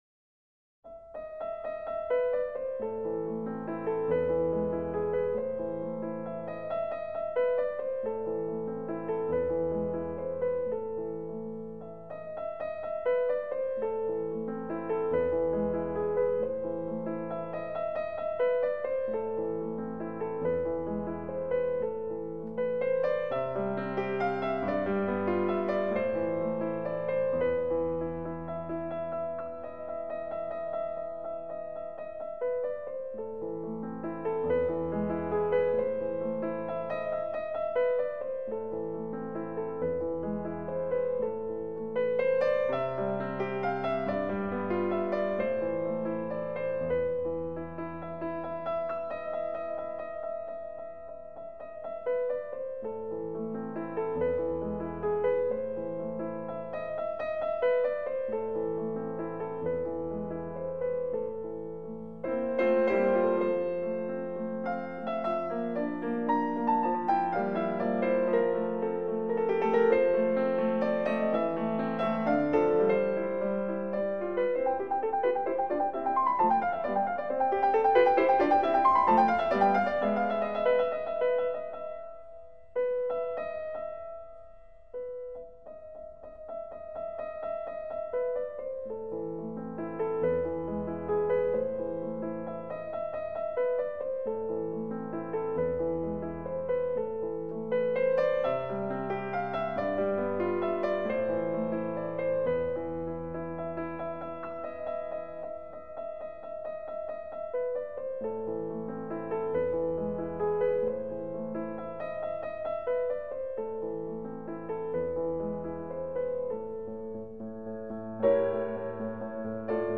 These are the pieces Jensen's piano plays for Dr. Ben Teller: